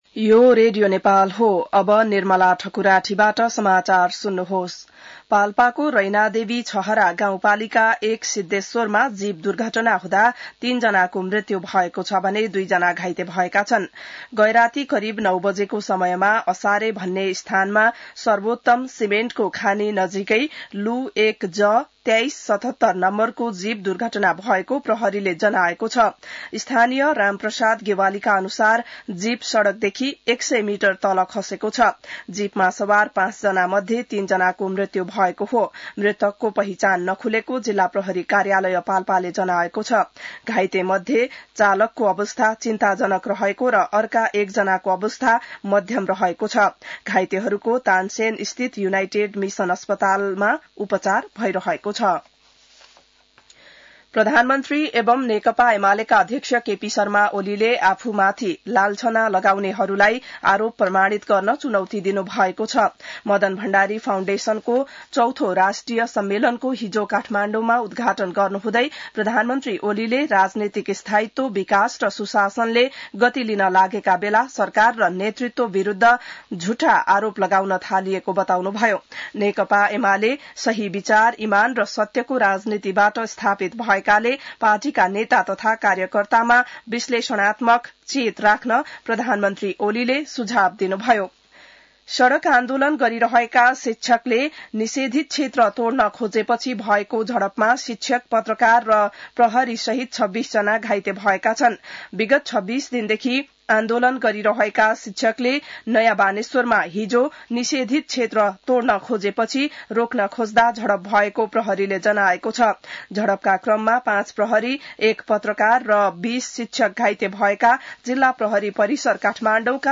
बिहान ६ बजेको नेपाली समाचार : १५ वैशाख , २०८२